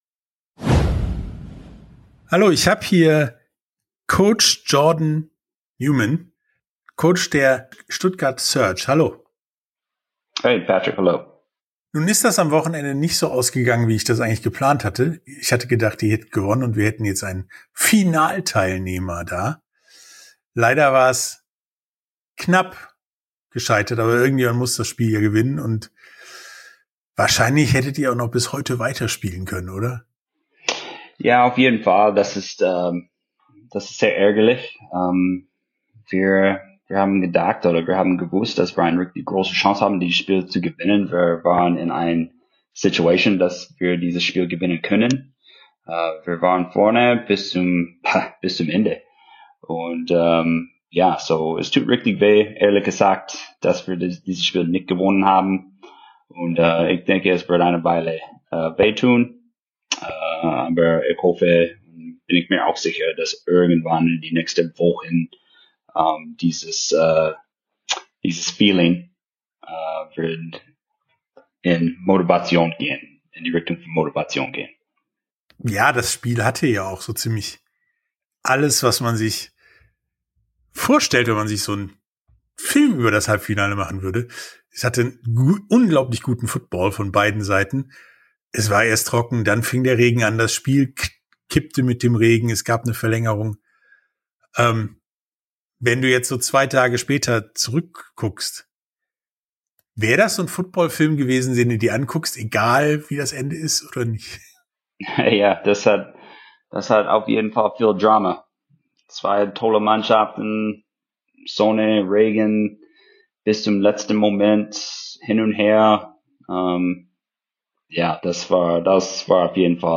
Sportstunde - Interview komplett